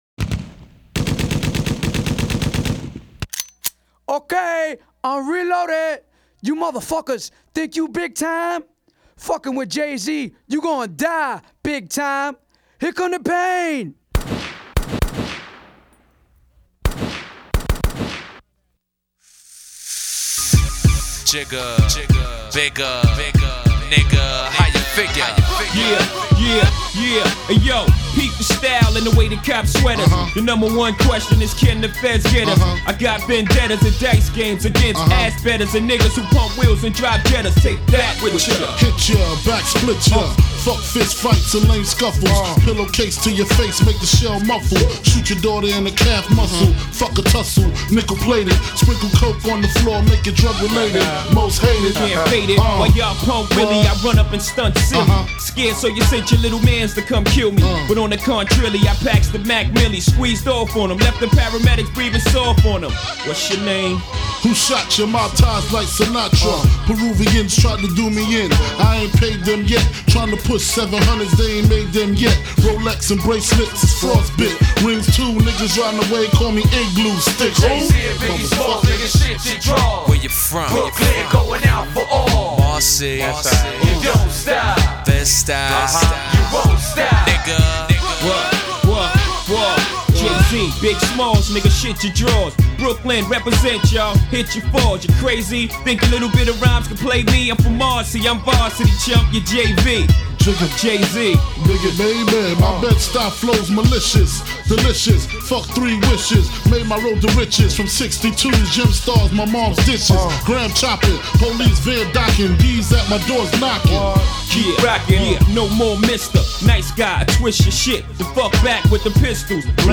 rap hip hop رپ هیپ هاپ